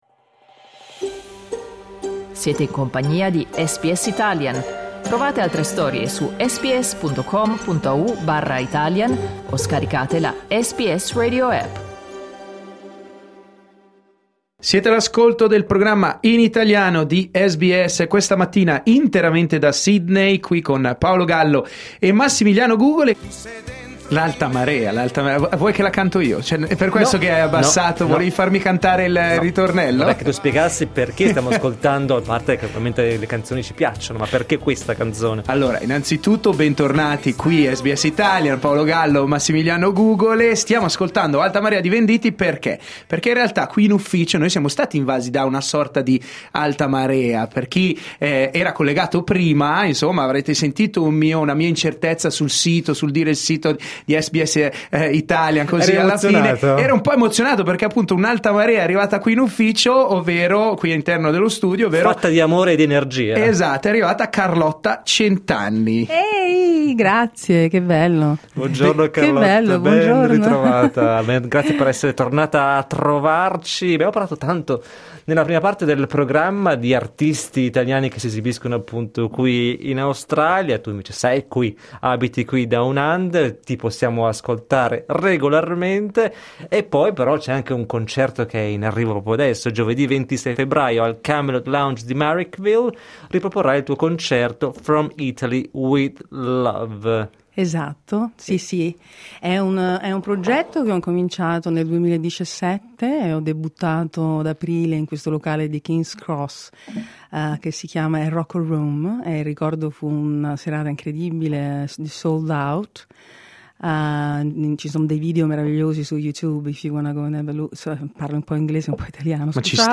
Ospite in diretta su SBS Italian